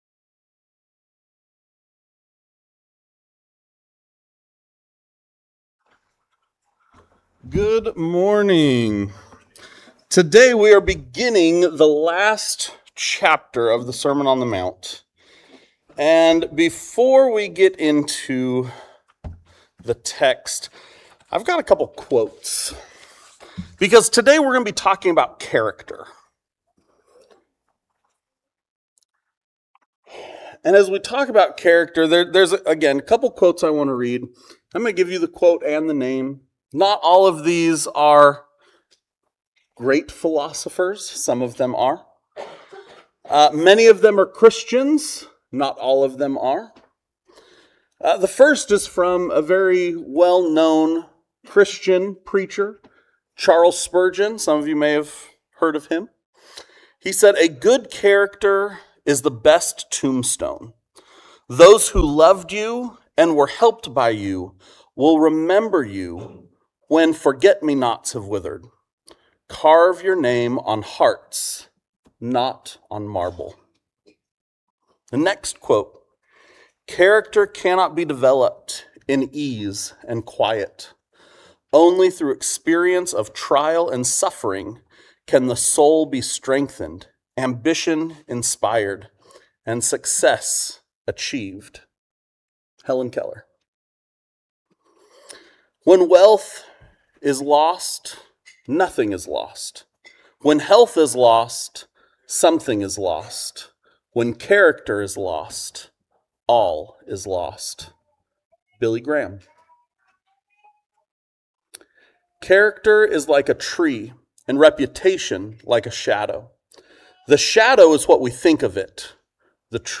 In this sermon, we break down Jesus's teaching on judgment and the importance of Christian character.